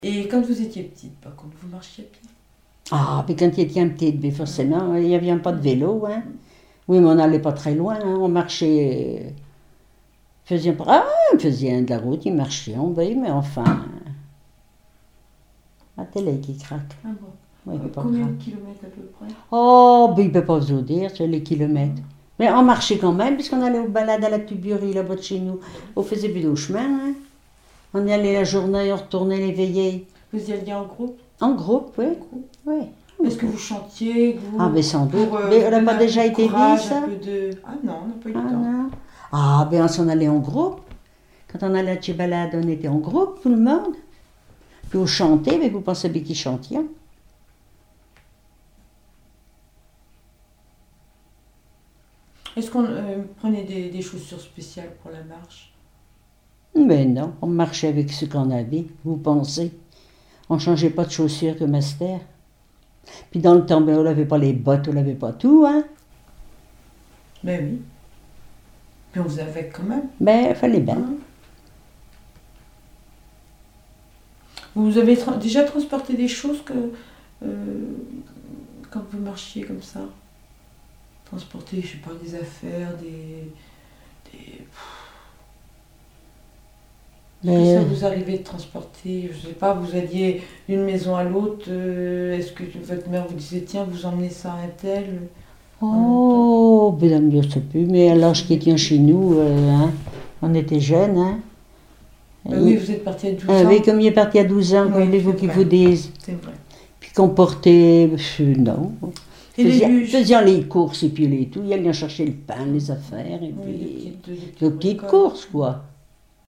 Enquête Arexcpo en Vendée-GAL Pays Sud-Vendée
Catégorie Témoignage